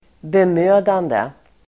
Uttal: [bem'ö:dande]